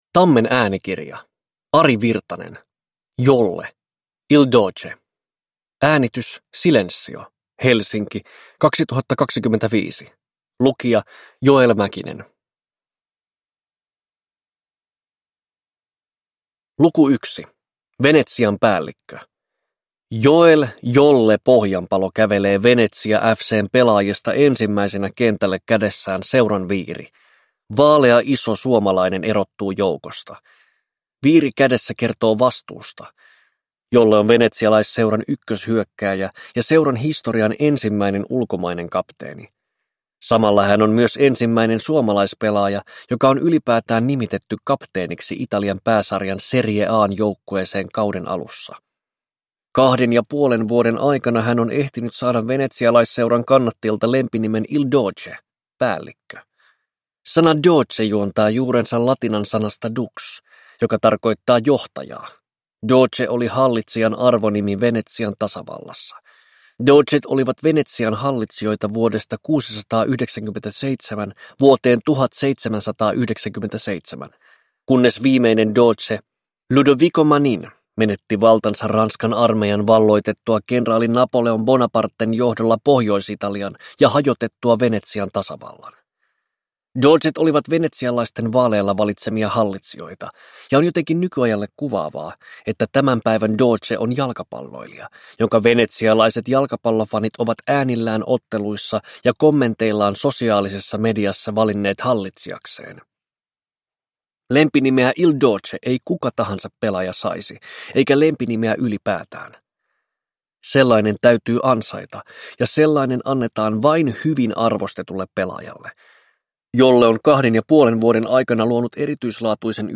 Jolle – Ljudbok